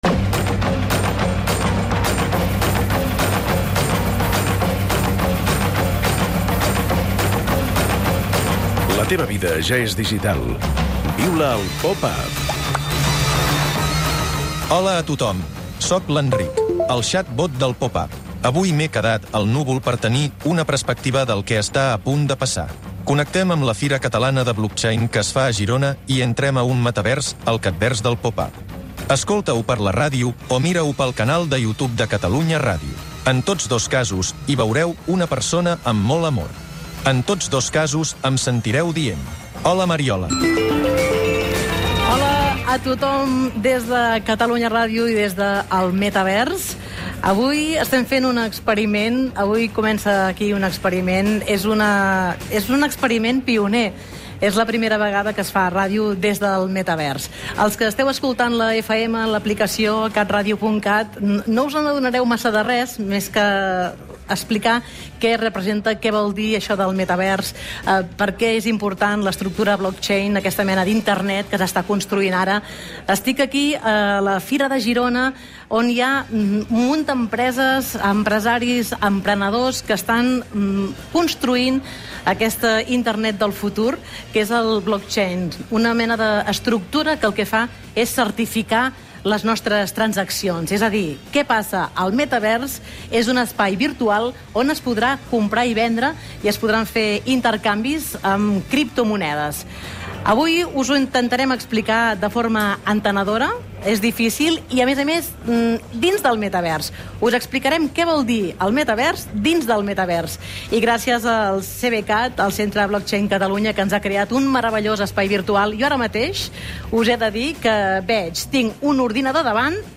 Beschreibung vor 3 Jahren Expliquem el metavers des d'un metavers. Des de la Fira Catalana del Blockchain de Girona i en l'entorn virtual del CatVers de Catalunya Ràdio, creat pel Centre Blockchain Catalunya (CBCAT).
I en aquest espai virtual també ens trobem amb Marc Parrot, que presenta el seu nou disc des dels estudis de Catalunya Ràdio a Barcelona.